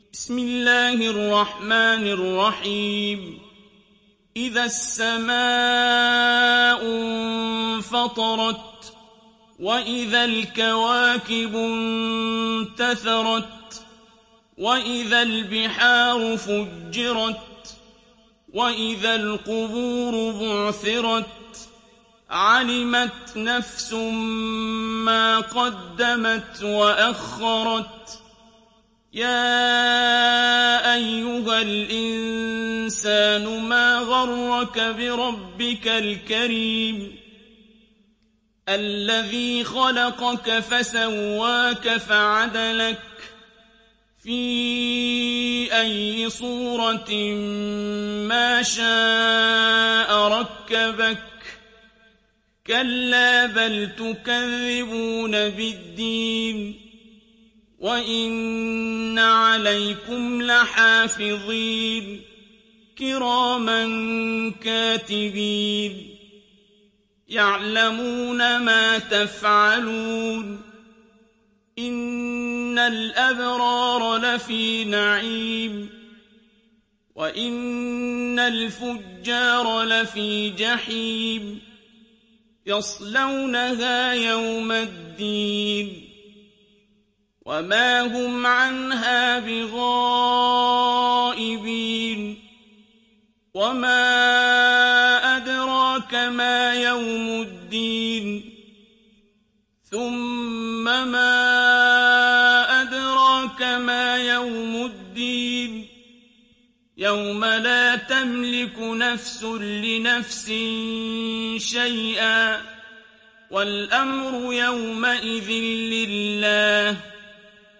تحميل سورة الانفطار mp3 بصوت عبد الباسط عبد الصمد برواية حفص عن عاصم, تحميل استماع القرآن الكريم على الجوال mp3 كاملا بروابط مباشرة وسريعة